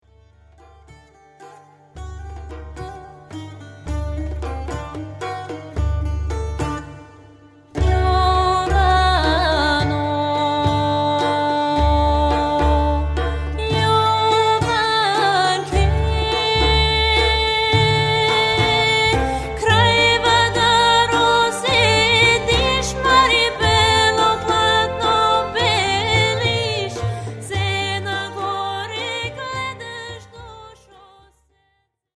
Vocal and beginner piano